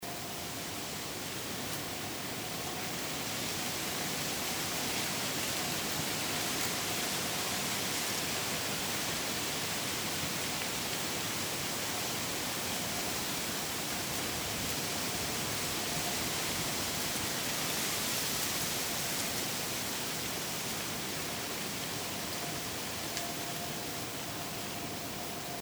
Włącza nagranie odgłosu szumu drzew.
SZUM-LIŚCI-ŁAGODNY.mp3